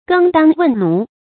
耕當問奴 注音： ㄍㄥ ㄉㄤ ㄨㄣˋ ㄋㄨˊ 讀音讀法： 意思解釋： 比喻辦事應該向內行請教。